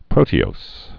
(prōtē-ōs, -ōz)